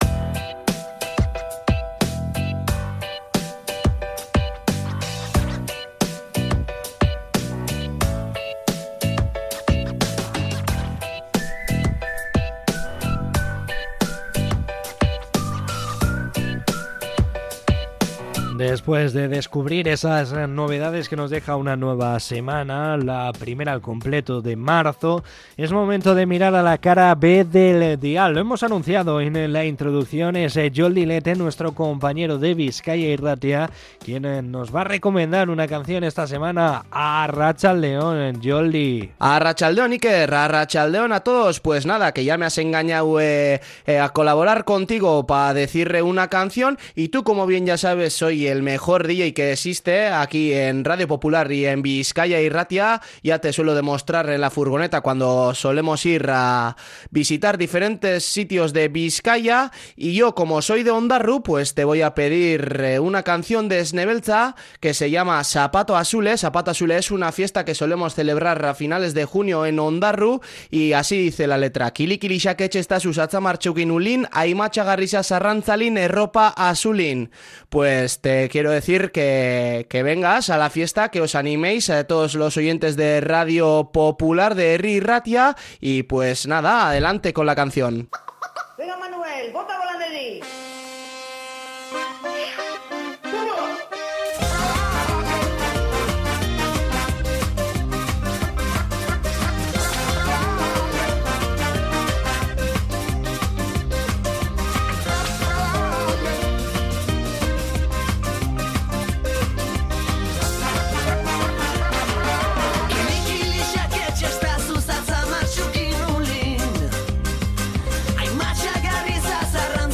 Podcast Música